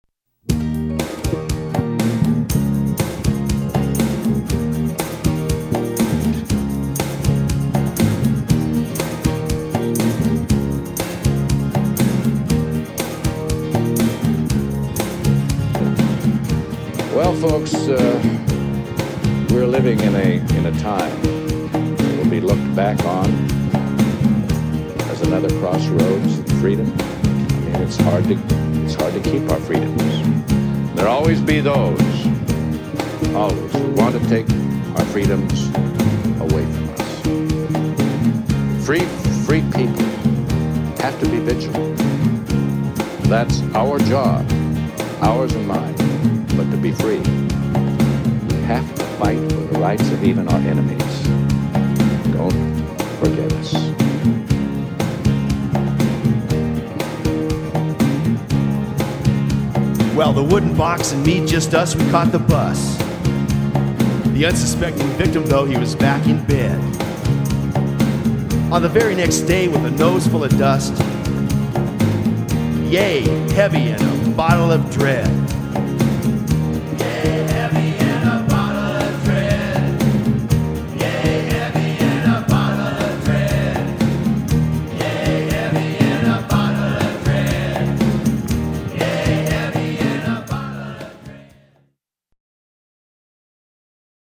scorchin' uptempo